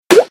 bottle_cork.ogg